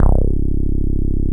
Index of /90_sSampleCDs/Best Service ProSamples vol.48 - Disco Fever [AKAI] 1CD/Partition D/SYNTH-BASSES